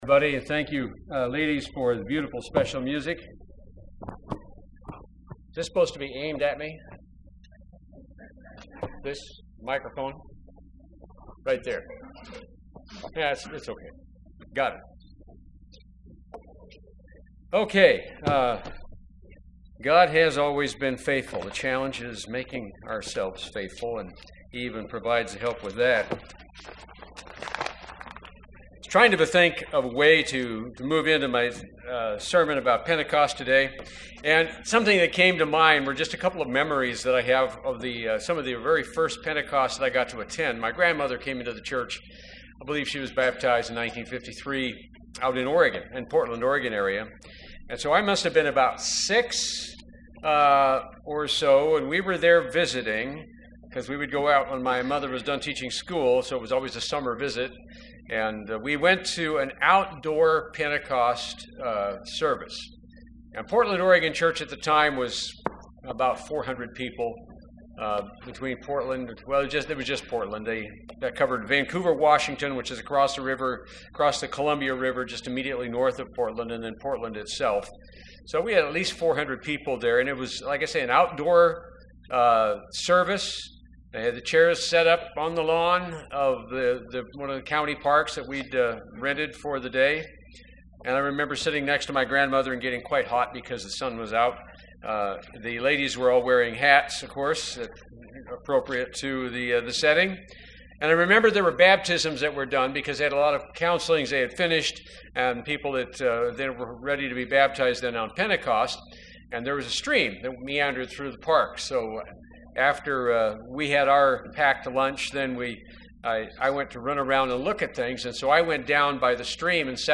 Pentecost Sermons and Sermonettes given in Washington Pennsylvanian as combined services for Wheeling W,VA, Pittsburgh PA, and Cambridge OH.